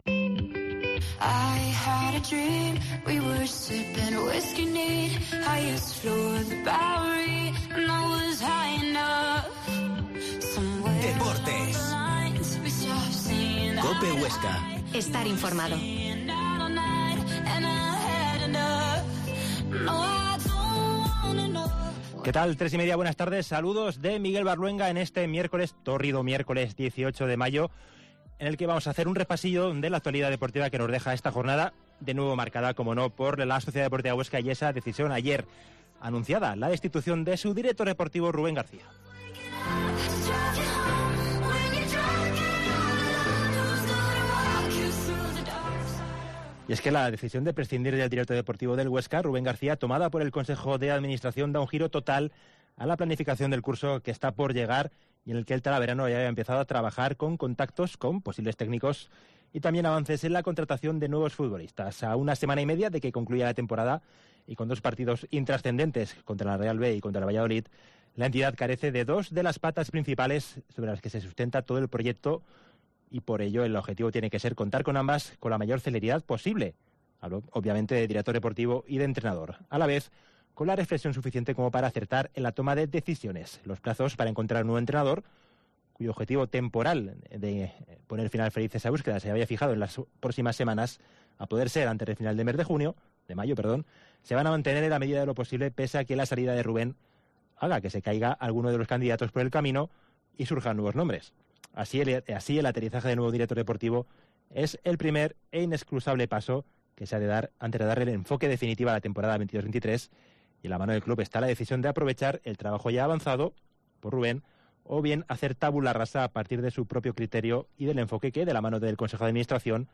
Programa de deportes
Entrevista